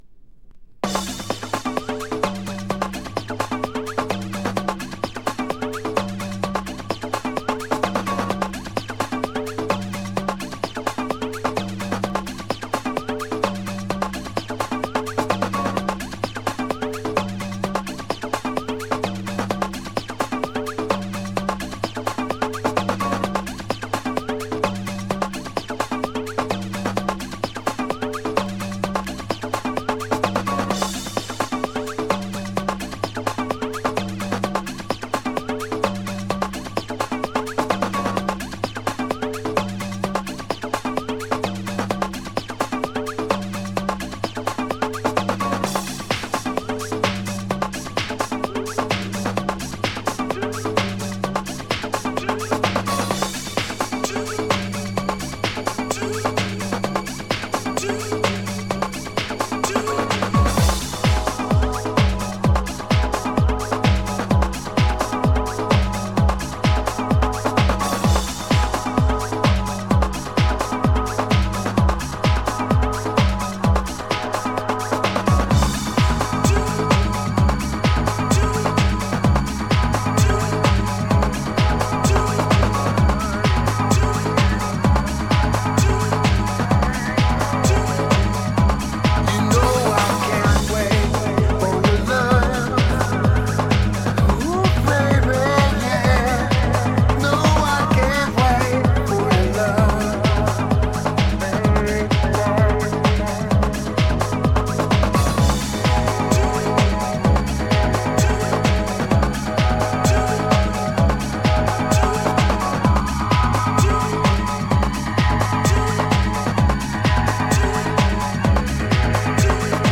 STYLE: funky house http